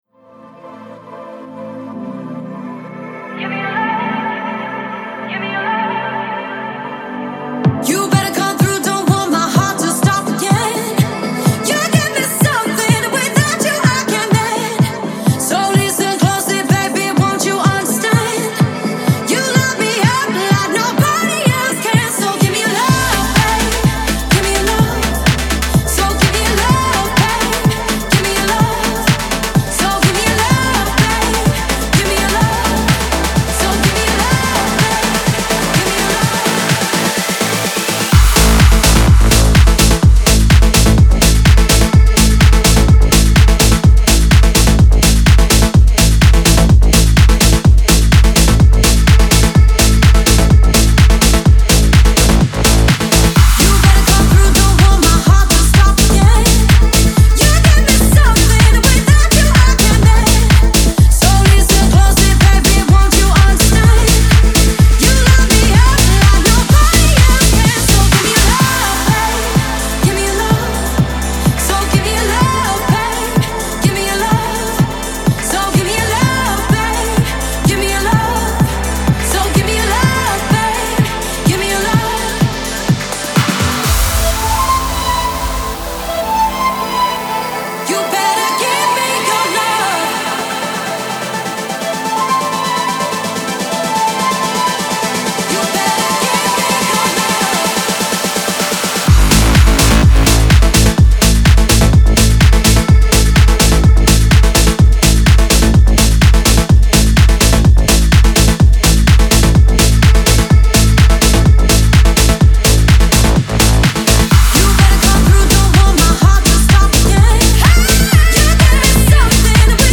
• Жанр: House, Electronic, Dance